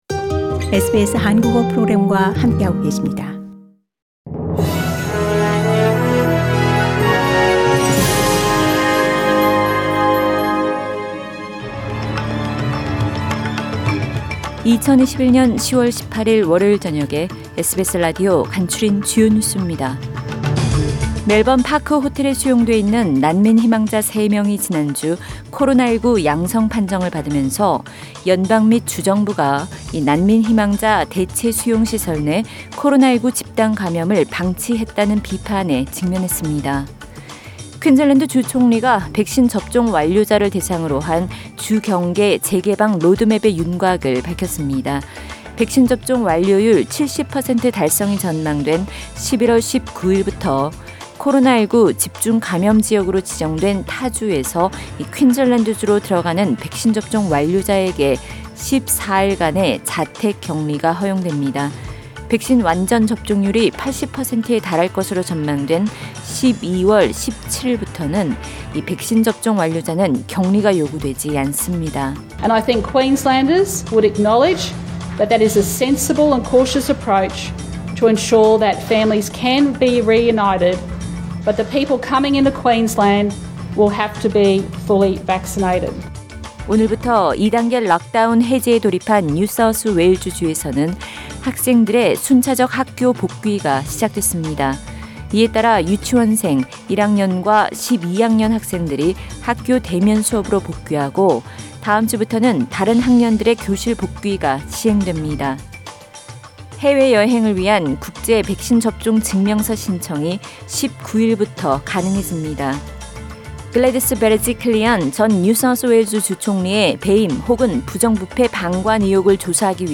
SBS News Outlines…2021년 10월 18일 저녁 주요 뉴스